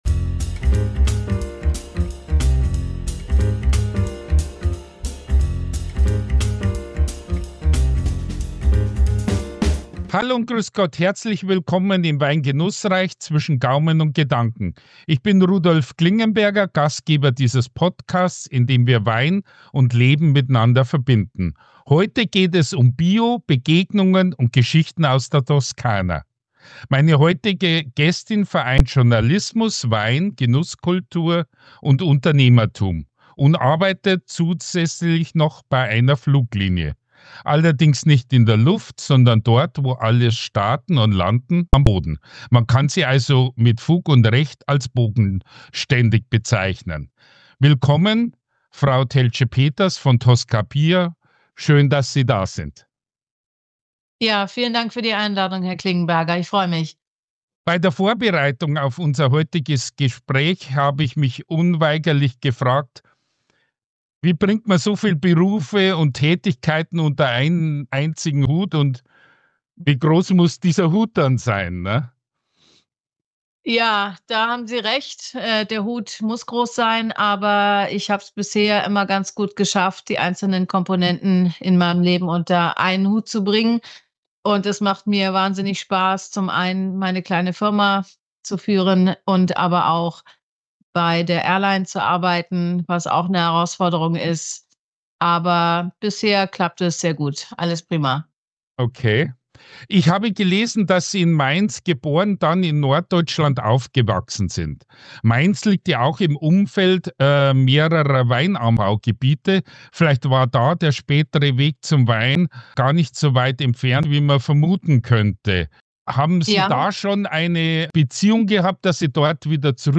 Ein Gespräch über Wein, Herkunft und Verantwortung – ruhig, reflektiert und persönlich.